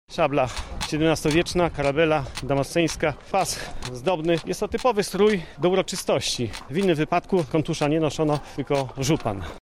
Po przemówieniach przyszedł czas na uroczystą defiladę z udziałem grup rekonstrukcyjnych.